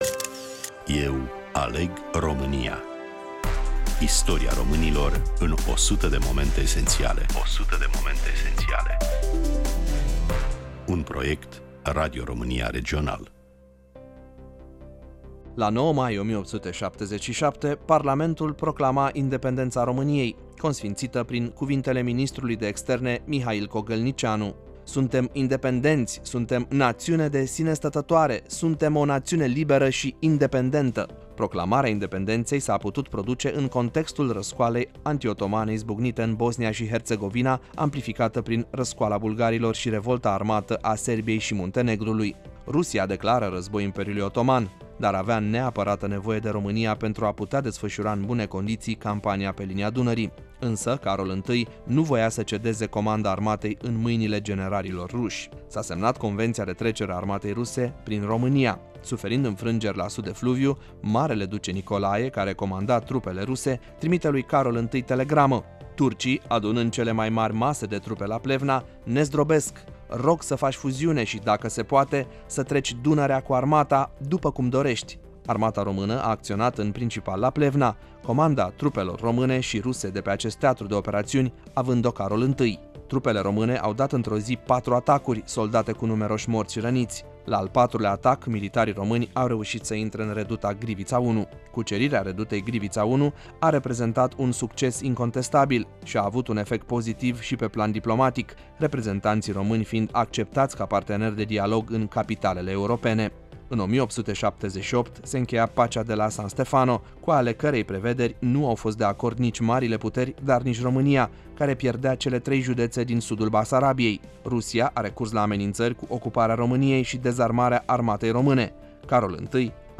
Prezentare, voice over